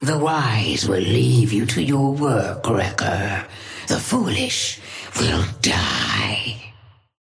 Sapphire Flame voice line - The wise will leave you to your work, Wrecker.
Patron_female_ally_wrecker_start_04.mp3